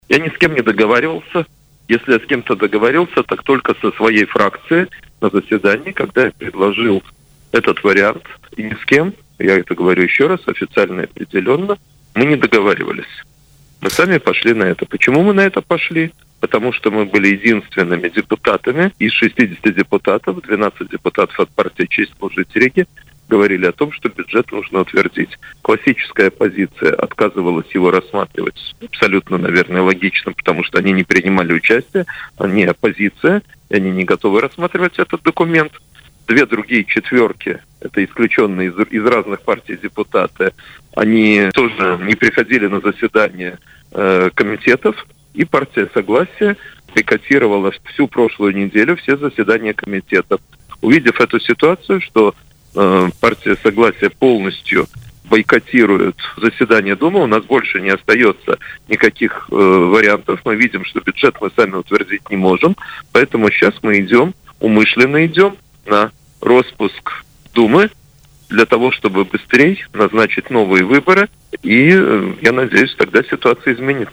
Между тем, в эфире радио Baltkom Буров категорически отрицал наличие какой-либо договоренности с оппозицией.